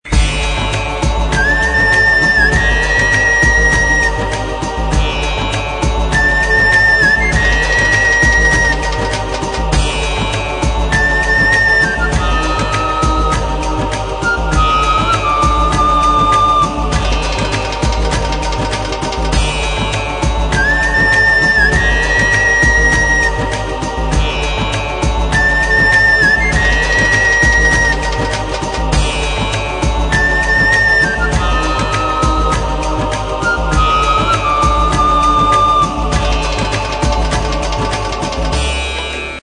Saxophone,
Sitar,